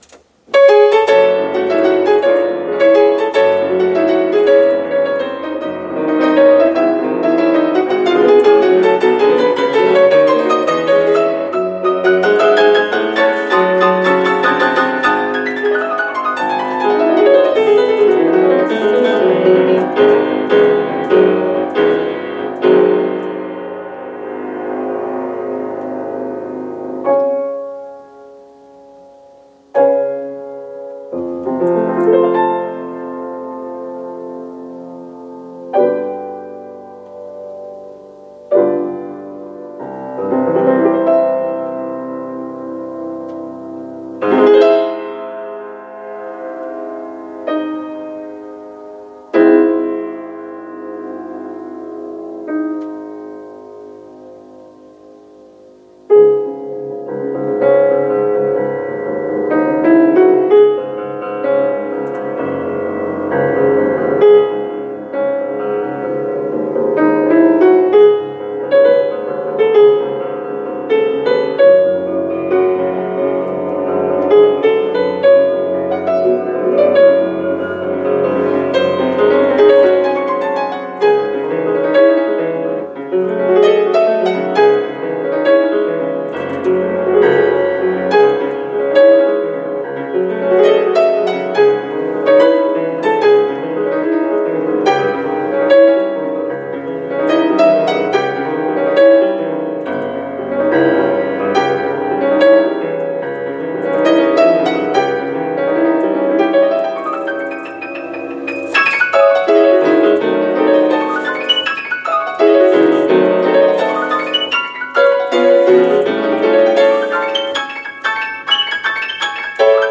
Piano Sonata no.3 in C-sharp minor, Third Movement, Allegro agitato
For me it’s a struggle between Beethovenian heaviness (Es muss sein!) v.s. Unbearable lightness of blues (trying to imitate the style).